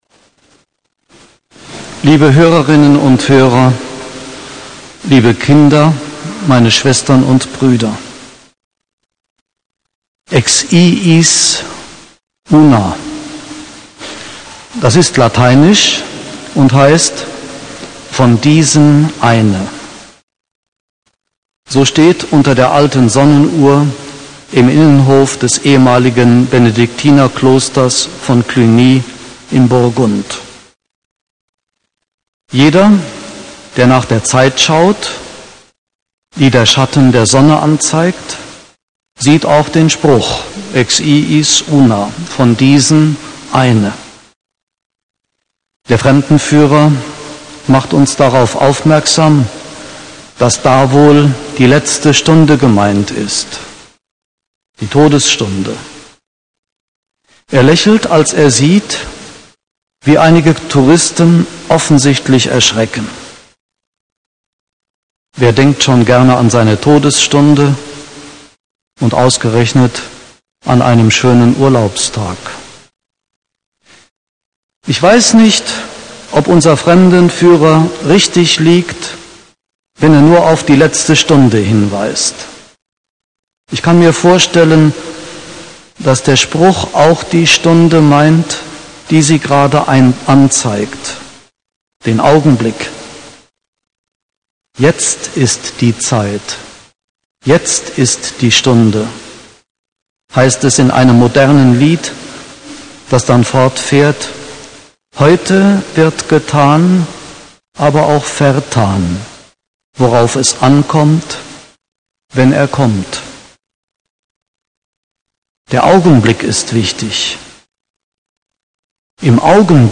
Predigt-Download
Die Adventspredigt aus dem Rundfunkgottesdienst am 30.11 steht nun als mp3-Datei zum Herunterladen zur Verfügung.